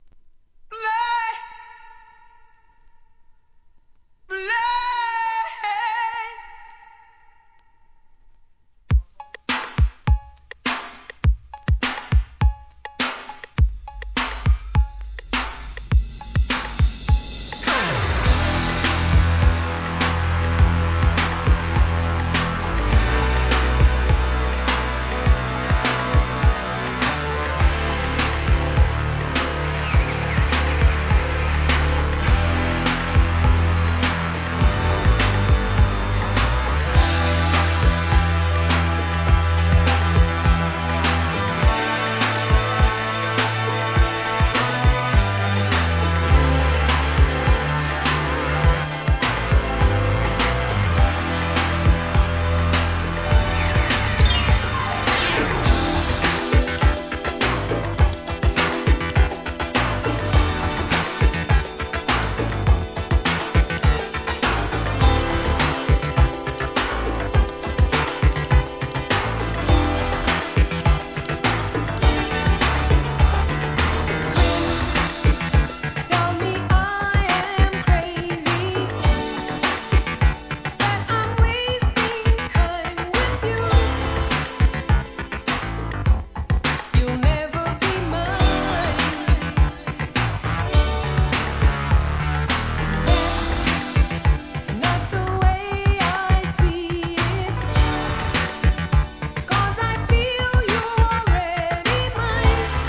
¥ 2,200 税込 関連カテゴリ SOUL/FUNK/etc...
9分OVERのLONG VERSIONです!!